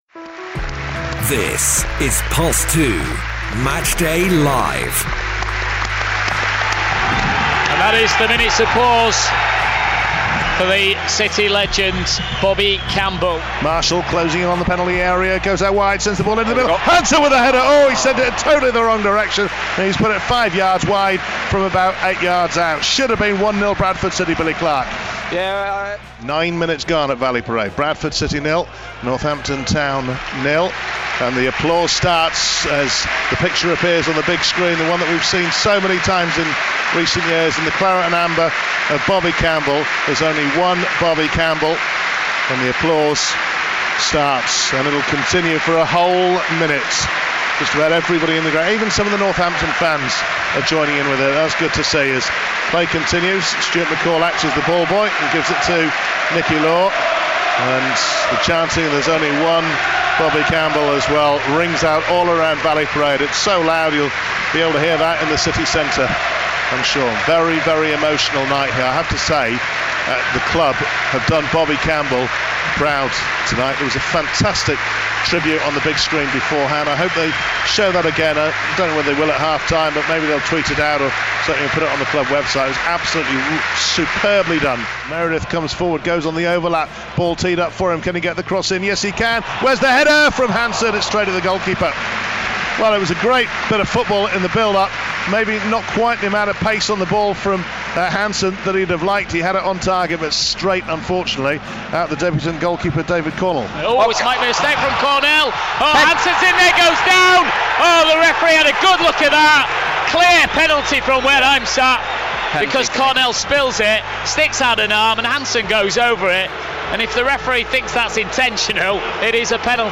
Headliner Embed Embed code See more options Share Facebook X Subscribe Share Facebook X Subscribe Next Stuart McCall post match interview.